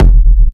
Industrial Techno Kick oneshot SC - F (6).wav
Nicely shaped, crispy and reverbed industrial techno kick, used for hard techno, peak time techno and other hard related genres.
industrial_techno_kick_oneshot_sc_-_f_(6)_4kt.ogg